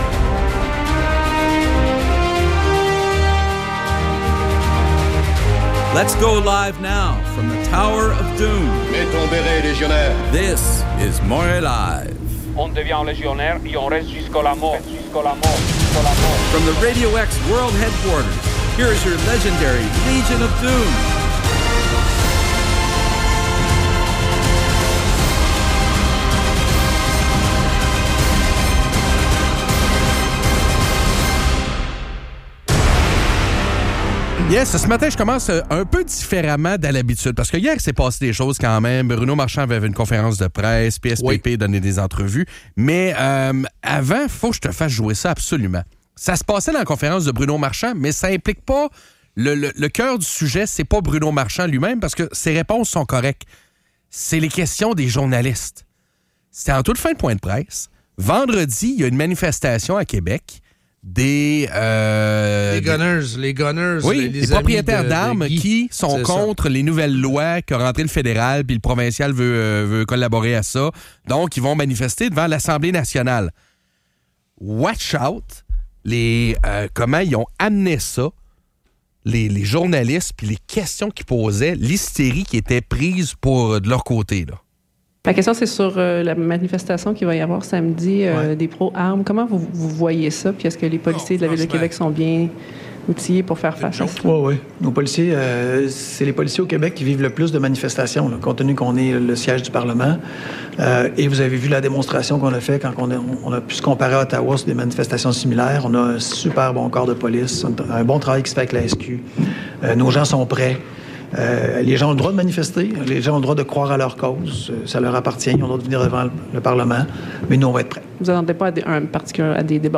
Une journaliste remet en question la légitimité d'une manifestation de propriétaires d'armes à feu à Québec, en suggérant qu'elle pourrait représenter un danger. Les animateurs défendent le droit de manifester tout en critiquant les biais des médias et la perception négative des propriétaires d'armes.